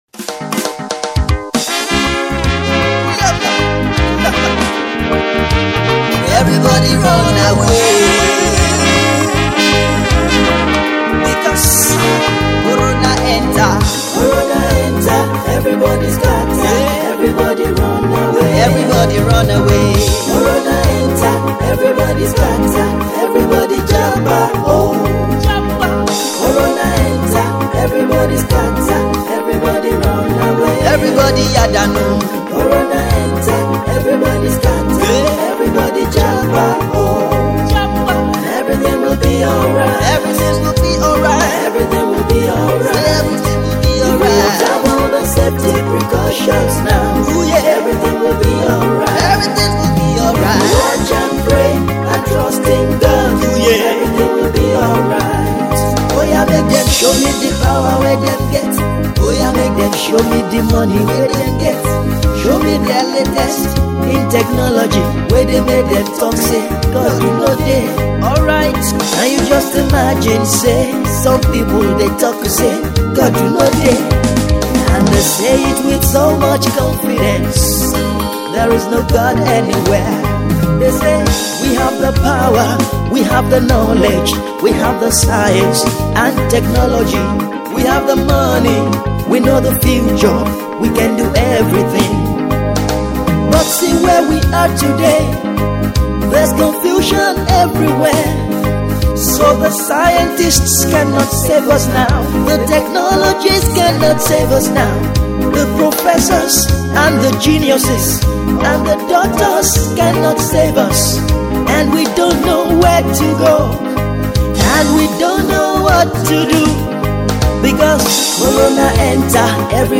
The ace music minister and trumpeter